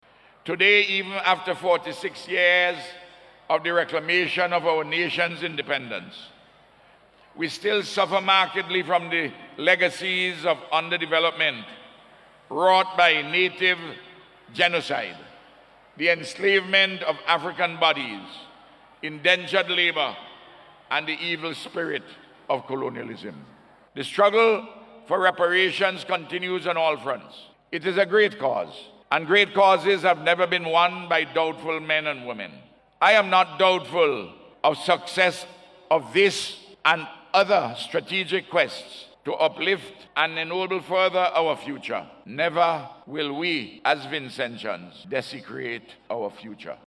This issue was addressed by Prime Minister Dr. Ralph Gonsalves during his address on the Occasion of the 46th Anniversary of Independence yesterday at the Arnos Vale Sporting Complex.